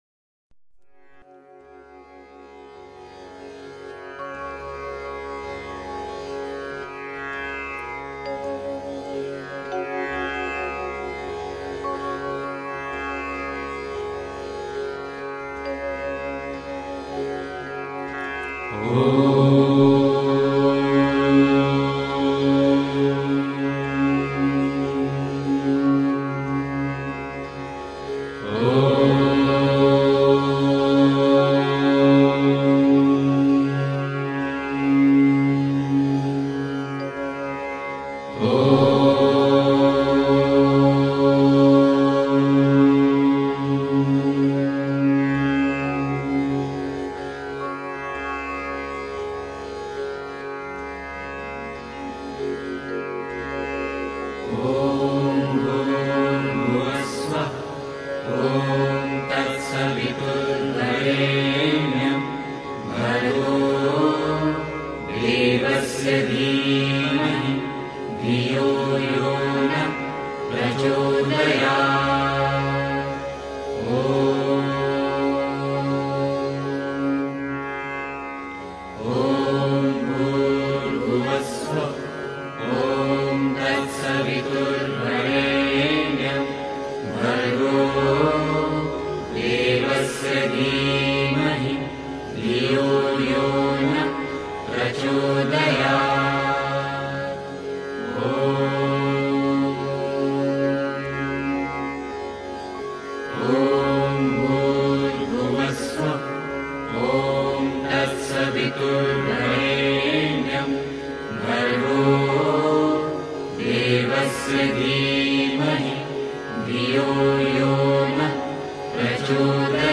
beautiful constant chanting